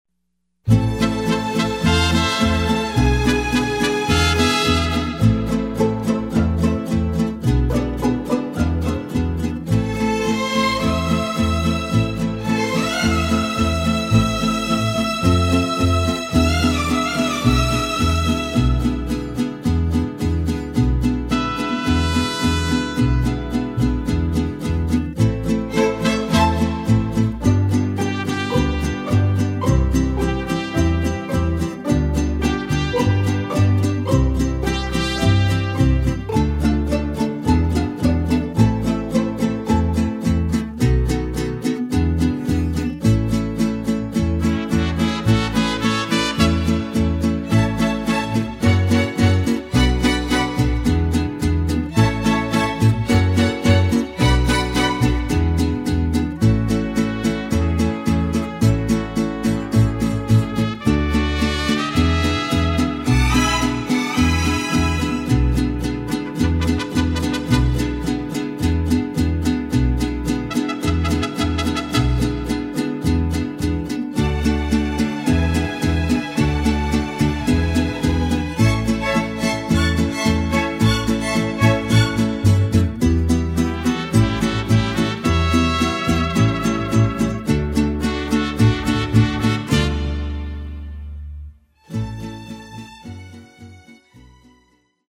Authentic Mariachi Music